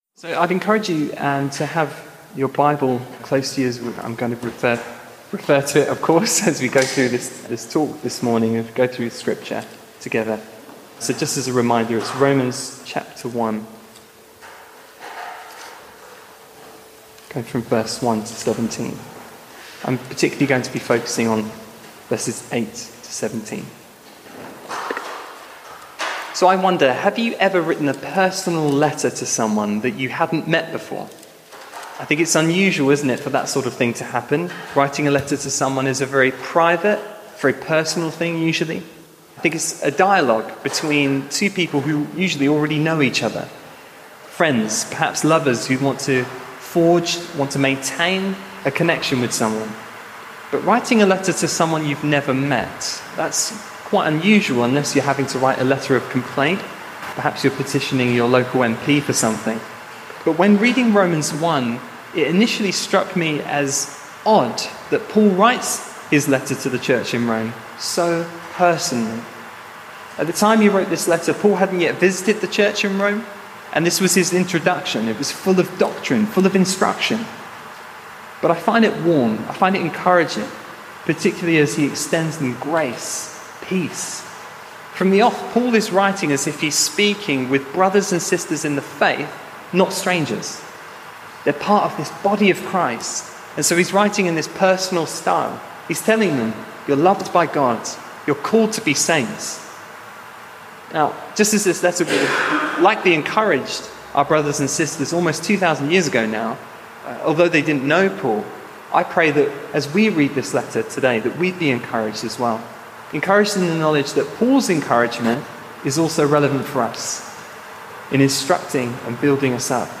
Sermons: Ashbourne Baptist Church 2025 | (Hope For Ashbourne YouTube channel)